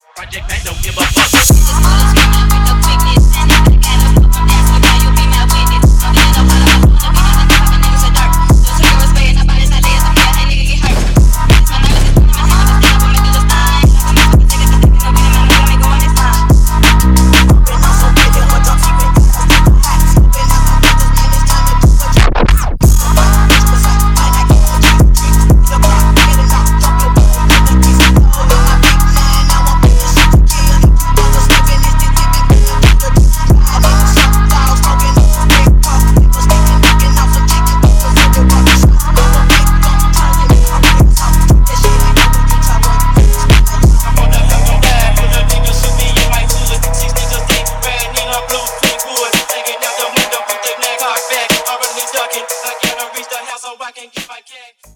Архив Рингтонов, Рэп рингтоны